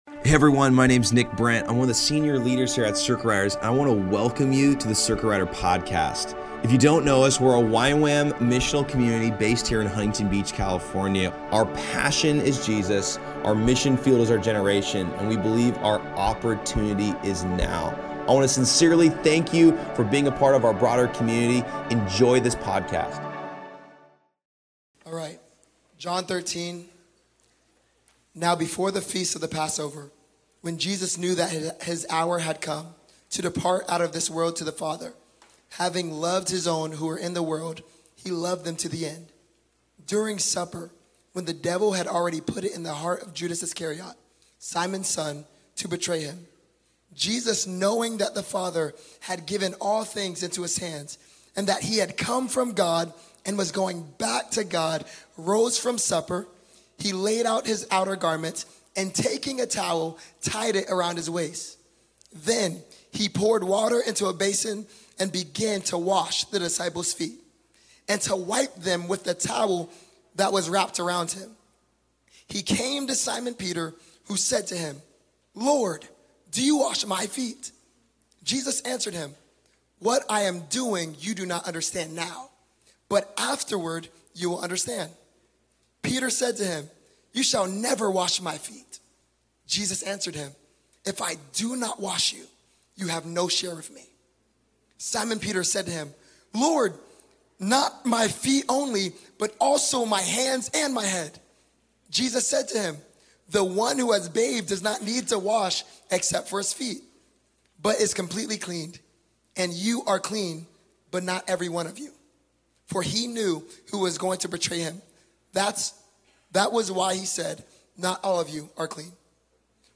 At Circuit Riders Monday Nights